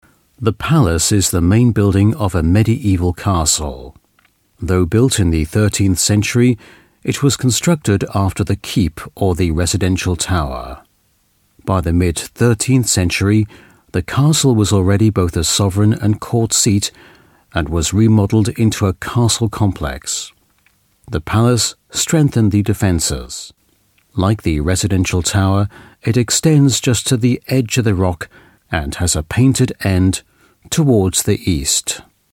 Native Speaker
Englisch (UK)
Audioguides